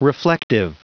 Prononciation du mot reflective en anglais (fichier audio)
Prononciation du mot : reflective